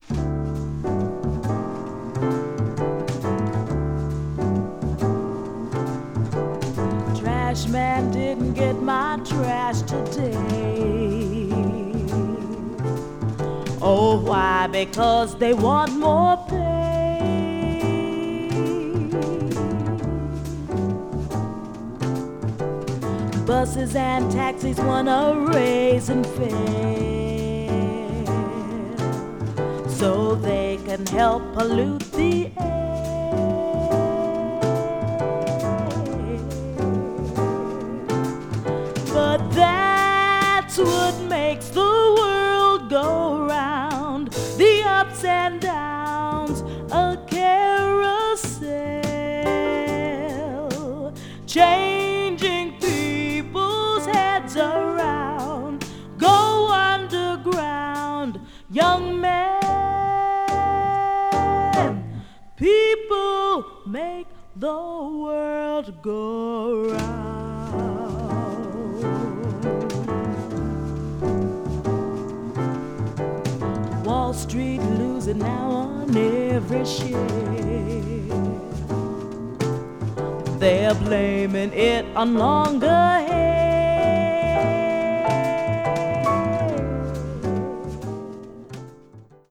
media : EX/EX(some slightly noise.)
floating, urban-infused groove rendition